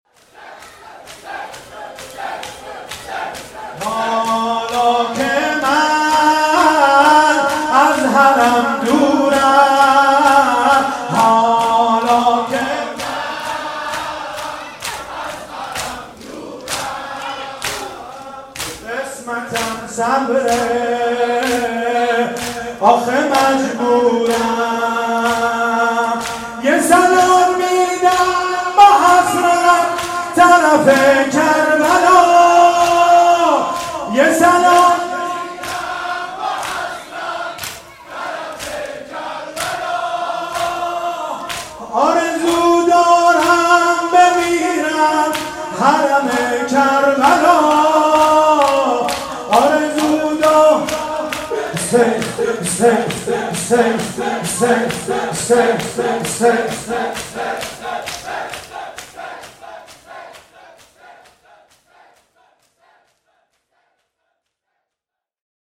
شب دوم محرم 94 شور